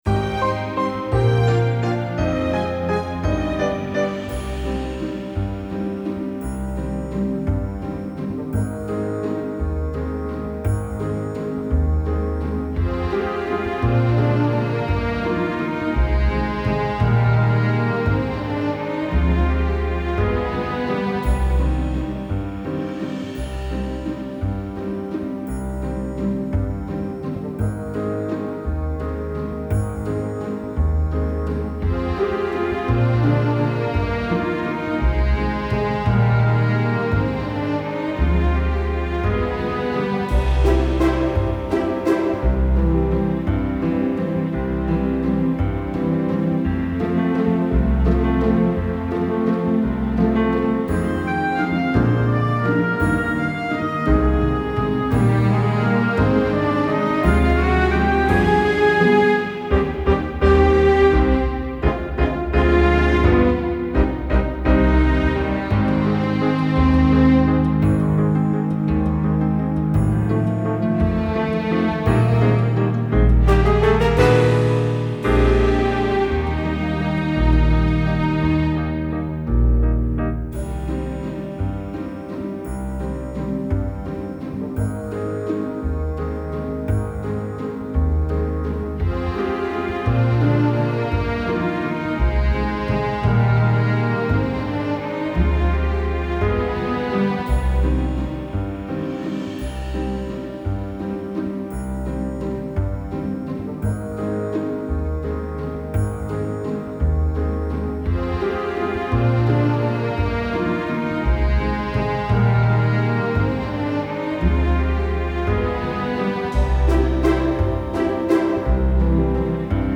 Recueil pour Clarinette